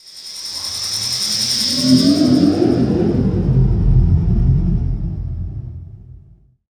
Ambi_Rise.wav